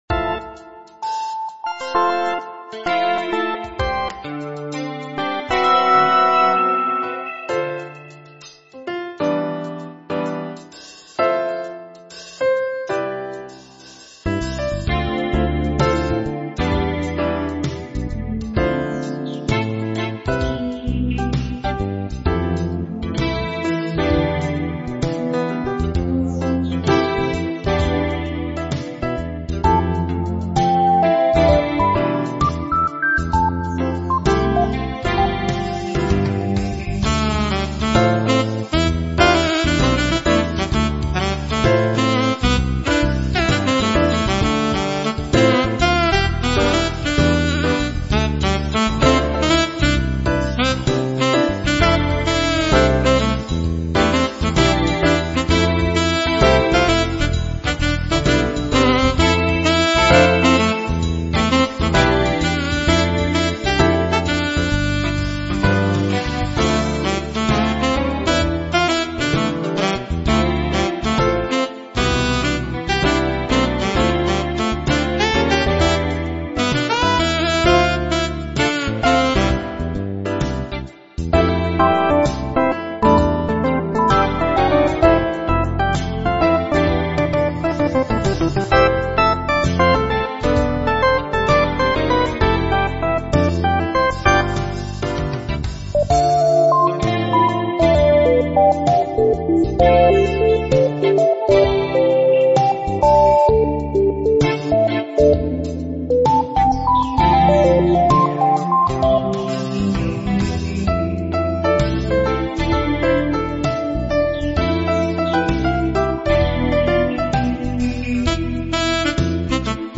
Playful Cool Jazz with Synth Saxophone lead